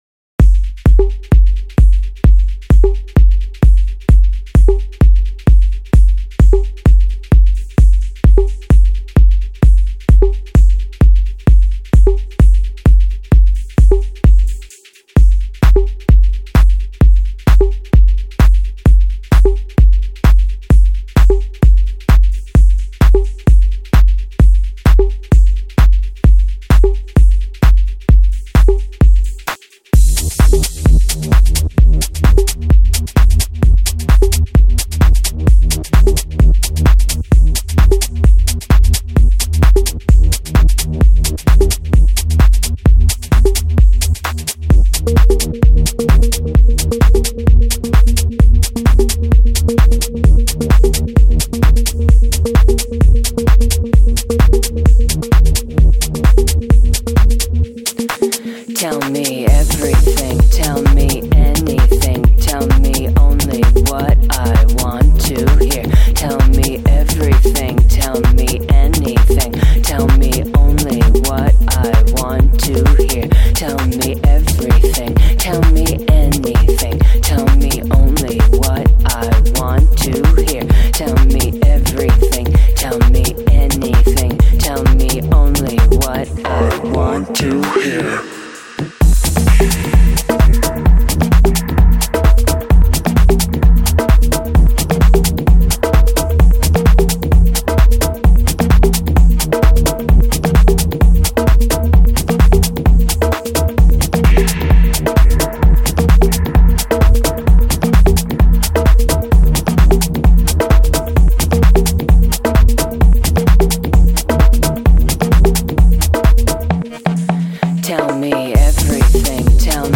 Жанр: Trance | Progressive